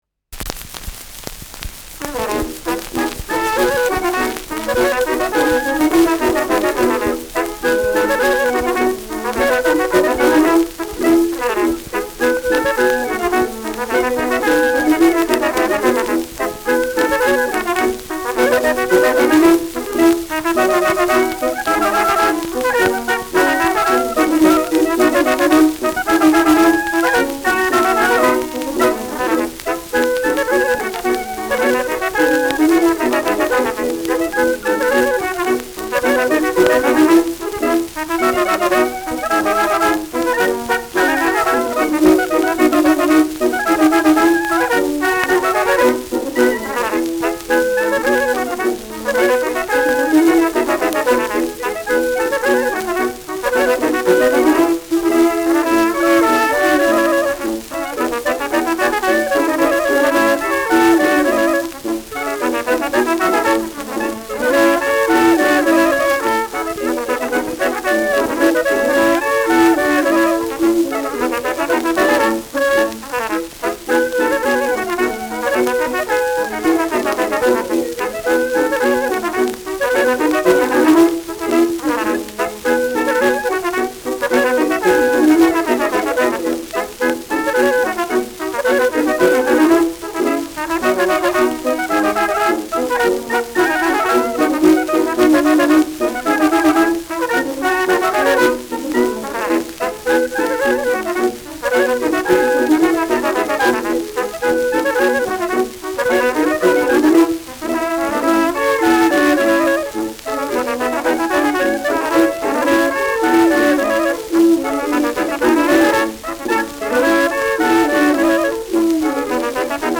Schellackplatte
präsentes Rauschen
Bischofshofner Bauernkapelle (Interpretation)